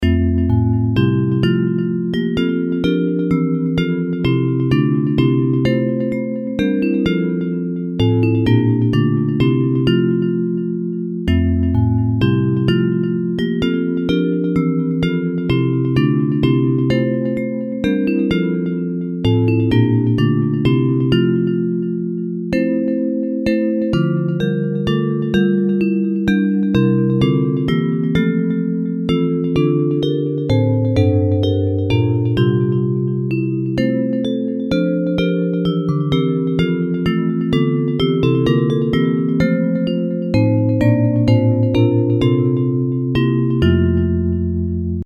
Hymns of praise
Bells Version